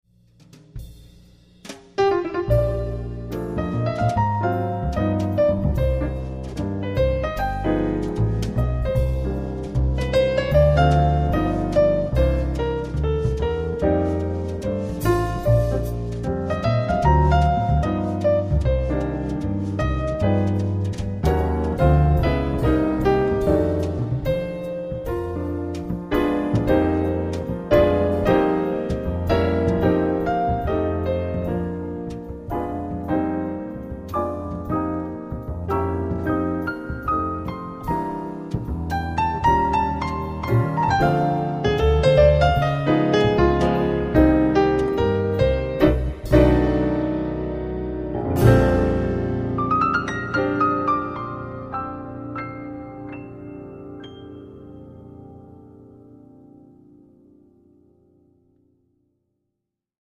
將古典大師的作品改編成爵士三重奏的型式，讓高雅的古典曲目增添了輕快寫意的風味